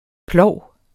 Udtale [ ˈplɒwˀ ]